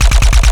Added more sound effects.
GUNAuto_RPU1 C Loop_01_SFRMS_SCIWPNS.wav